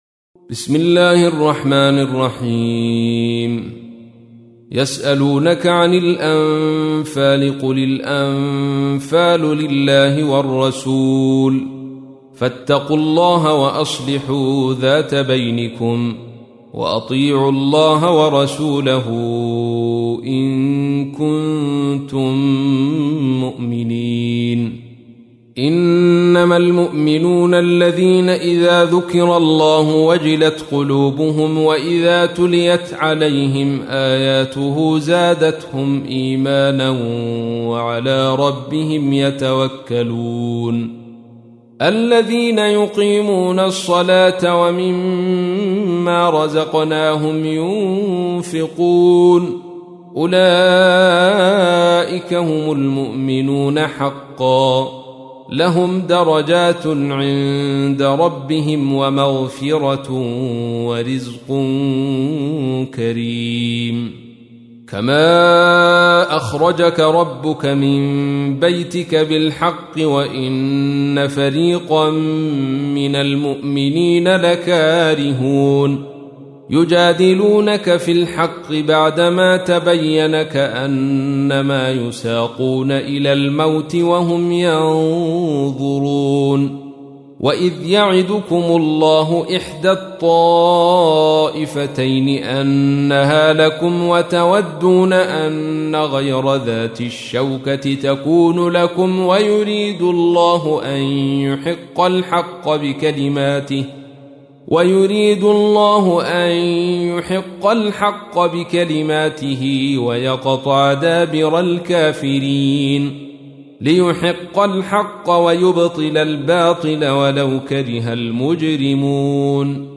تحميل : 8. سورة الأنفال / القارئ عبد الرشيد صوفي / القرآن الكريم / موقع يا حسين